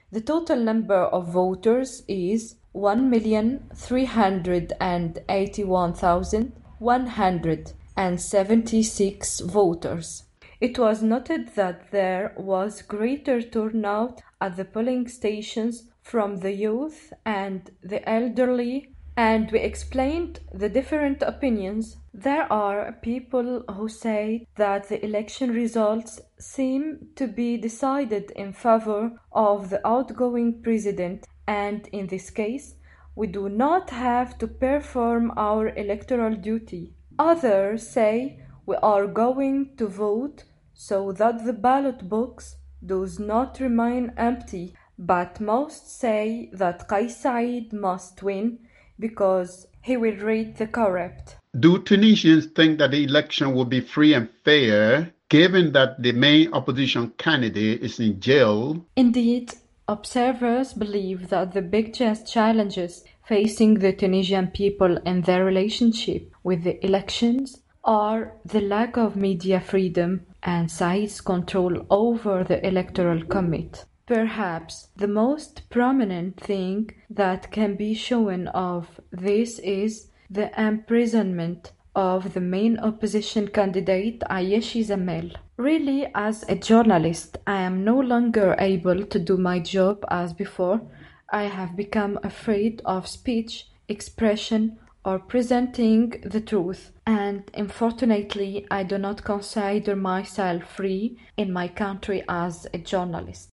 joins us from the Tunisian capital, Tunis